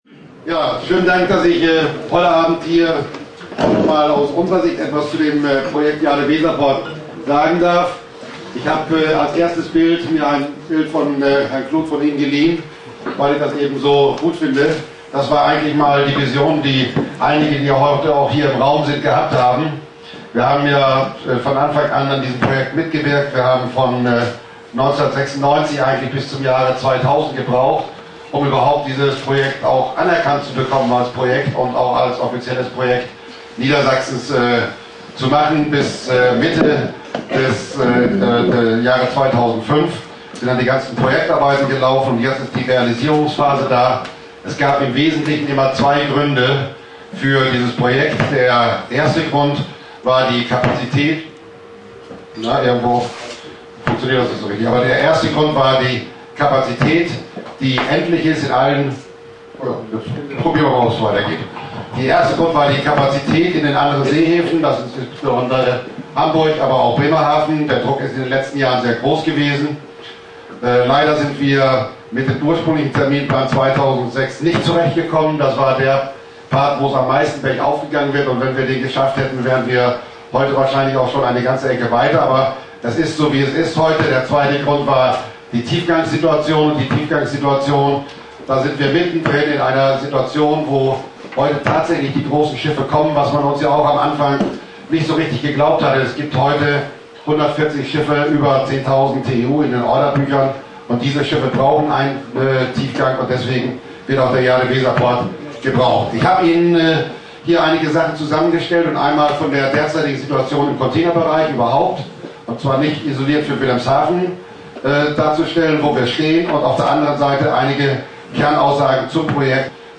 Stammtisch-Diskussion